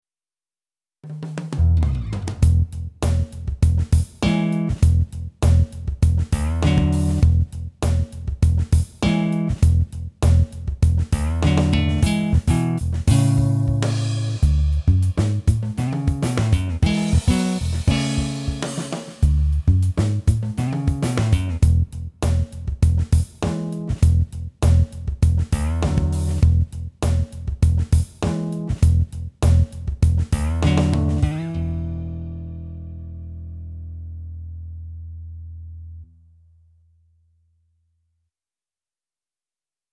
Manytone Acoustic Electric Bass
Includes Multi-velocity full length samples as well as Harmonics, Mute notes, Slides and other FX.
manytone_ovacoustic_bass_guitar_demo1.mp3